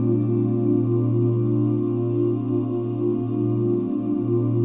OOH A#MAJ9.wav